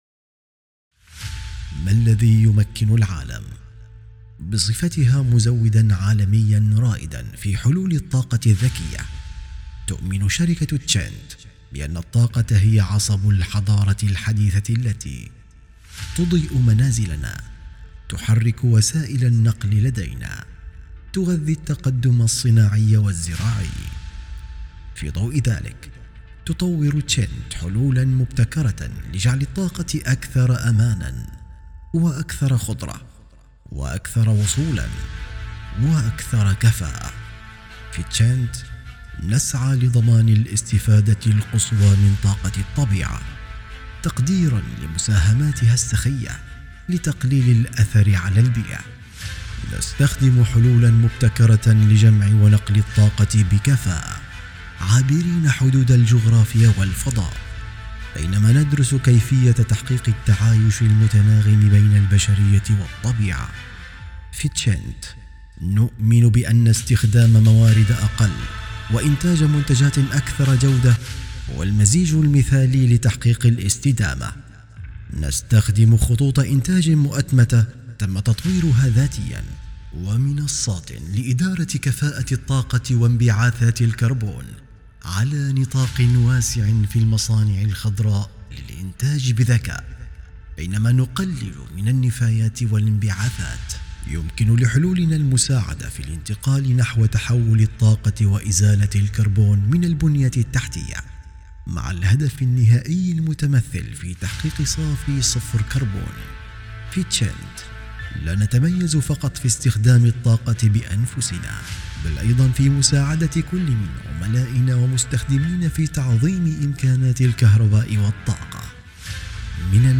عرض تقديمي لشركة مختصة بالطاقة – لغة عربية فصحى